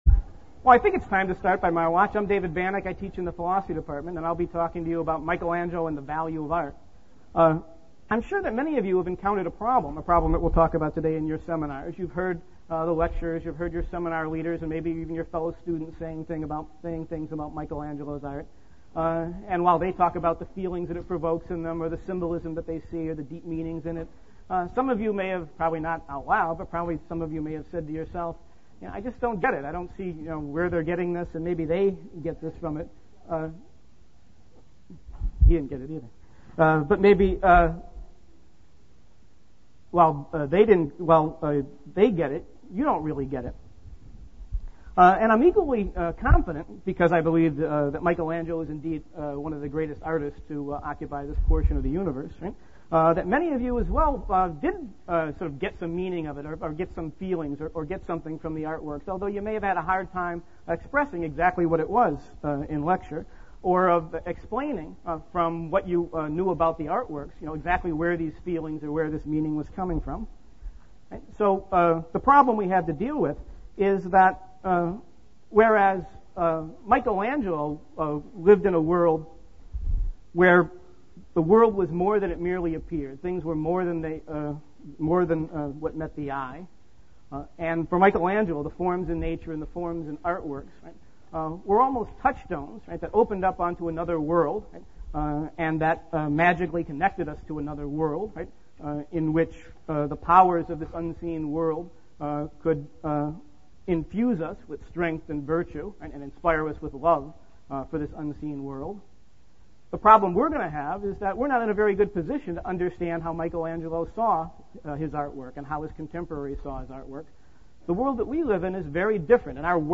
Lecture Recording